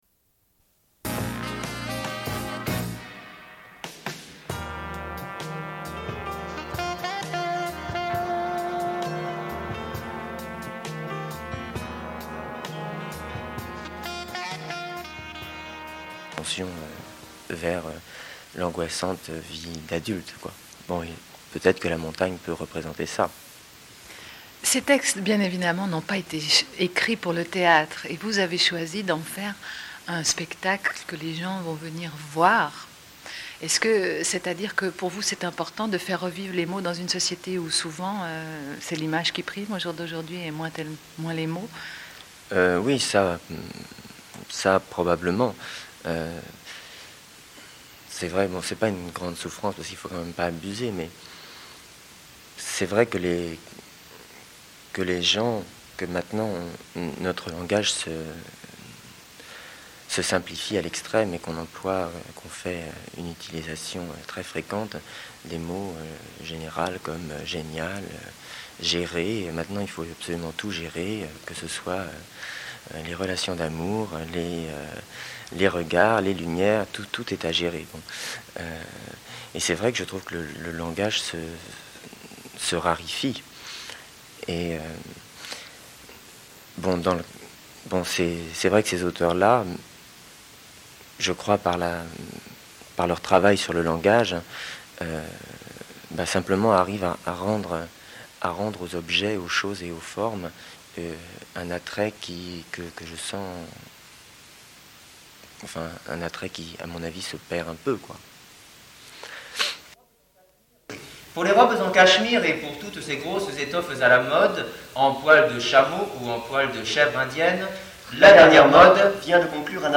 Une cassette audio, face A00:31:23
Suite et fin de l'émission sur le Festival de la Bâtie. Diffusion d'entretiens.